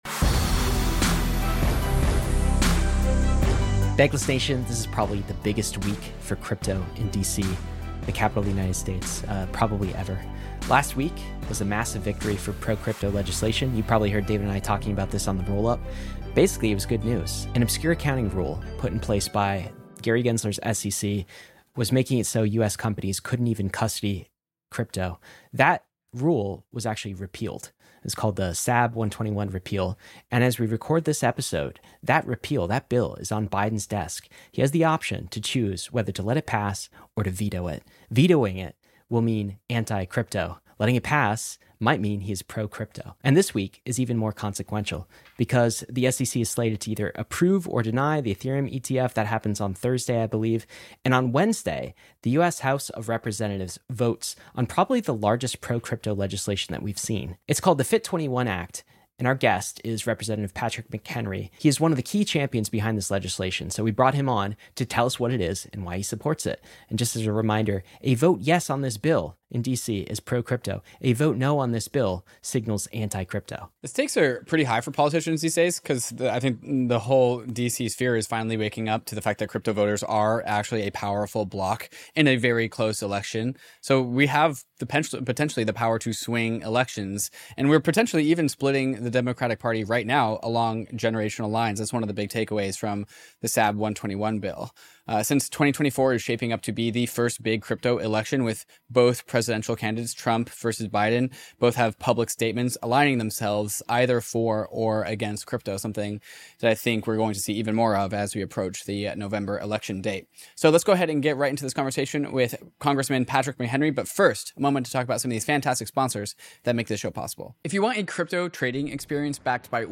This is a huge week for crypto in DC and we have on Congressman Patrick McHenry to tell us all about it. Three big things: 1) SAB 121 repeal bill going to Biden’s desk - will he let it pass or veto? 2) SEC approve/deny Ethereum ETF - decision due thursday. 3) House vote on FIT bill you’ve been championing - well talk about that bill.